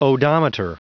Prononciation du mot odometer en anglais (fichier audio)
Prononciation du mot : odometer